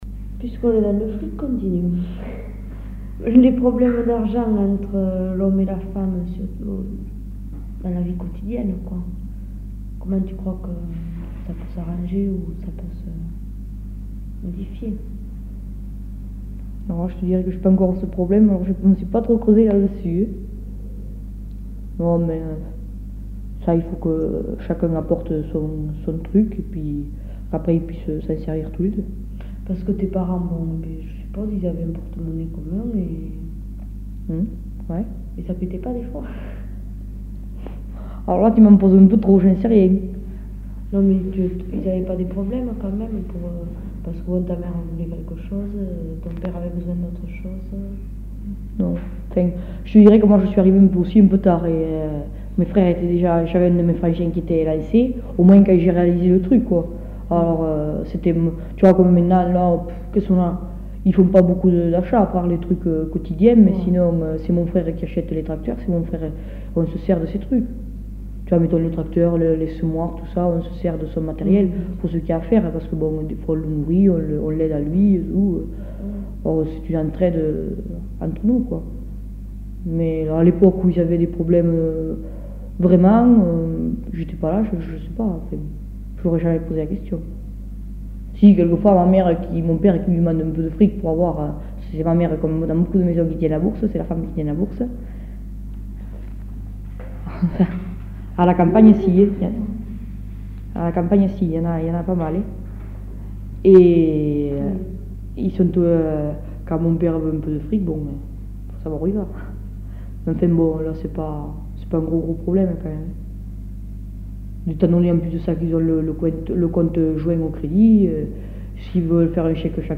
Lieu : Montamat
Genre : témoignage thématique